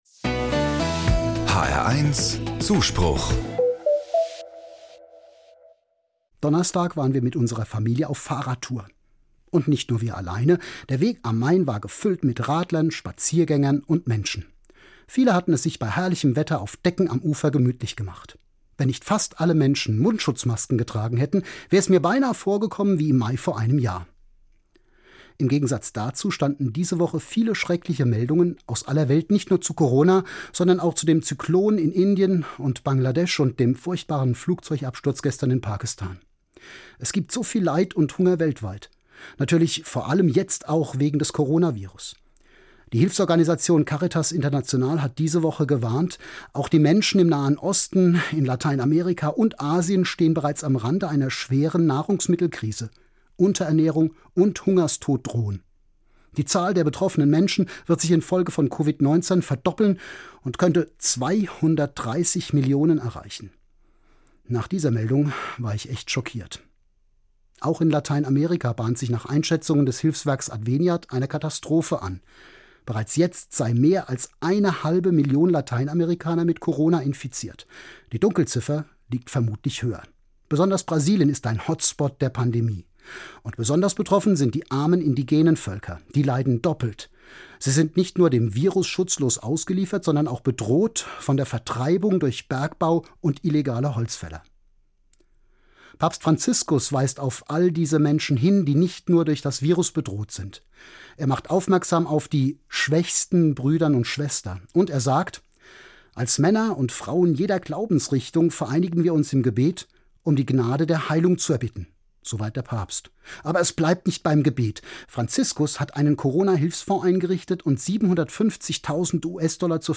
Katholischer Pastoralreferent, Frankfurt